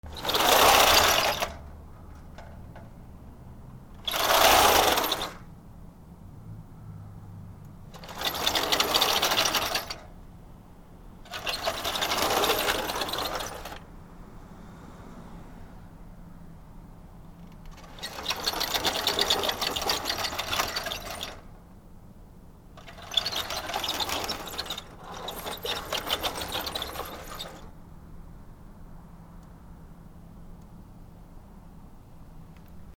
/ K｜フォーリー(開閉) / K05 ｜ドア(扉)
引き戸 檻